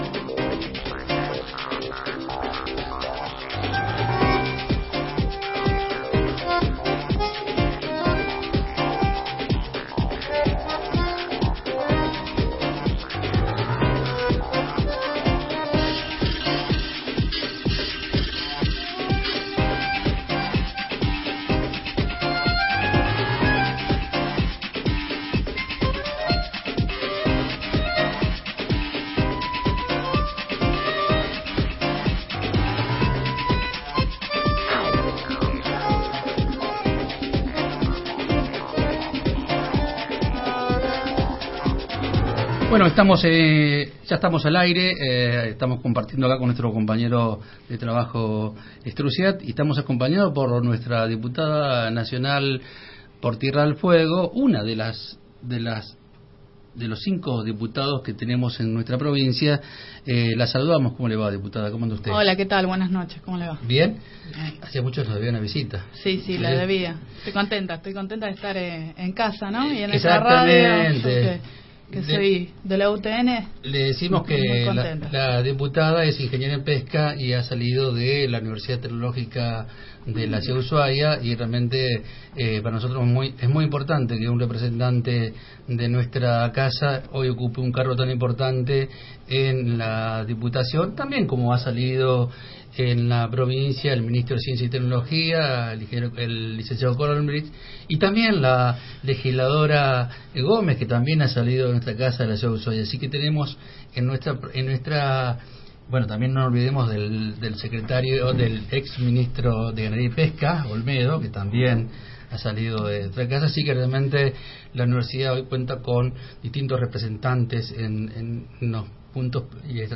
La Diputada del Frente para la Victoria, Ana Carol, visitó los estudios de Radio Universidad (93.5 MHZ).